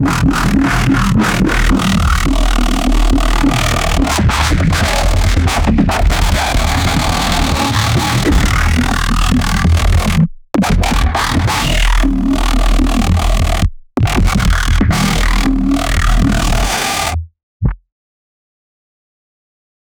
Royalty-free bass-music sound effects
Lower the pitch to where it is hitting notes between 40 and 100 hertz 0:15 extremely technical collection of intricate liquid and neuro dubstep bass hits 0:20